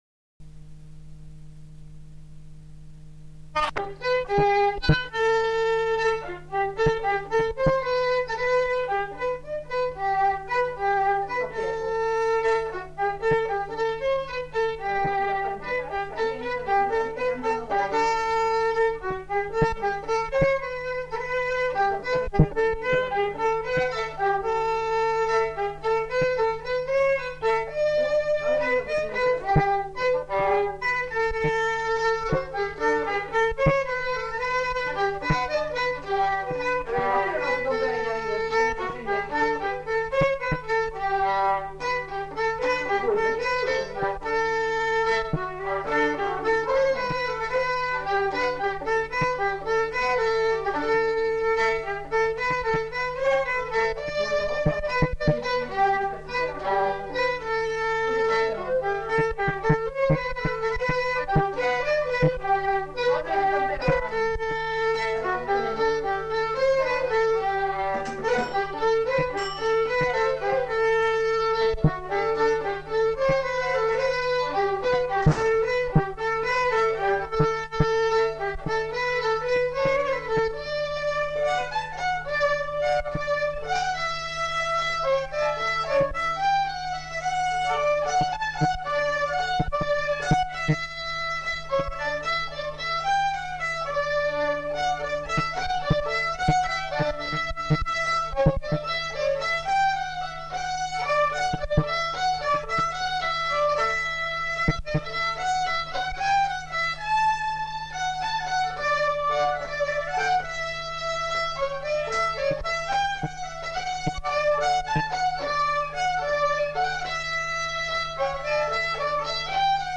Aire culturelle : Lugues
Lieu : Saint-Michel-de-Castelnau
Genre : morceau instrumental
Instrument de musique : violon
Danse : valse
Notes consultables : 2 violons.